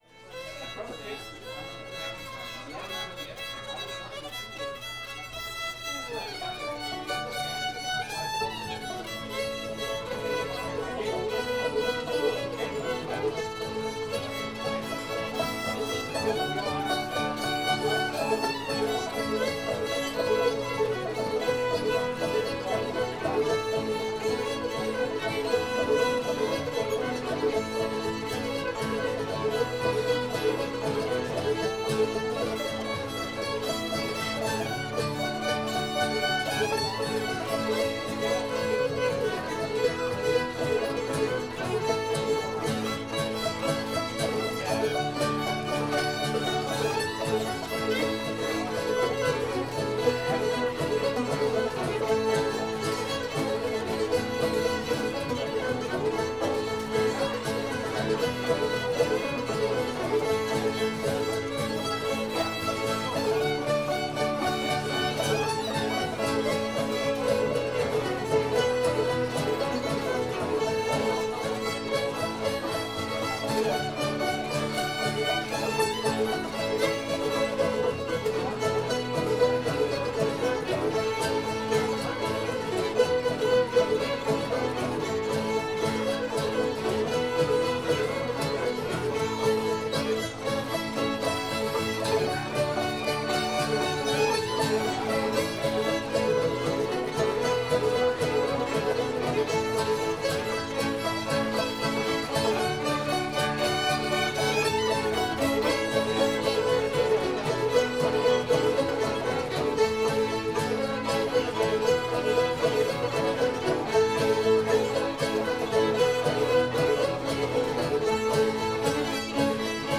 wish i had my time again [A]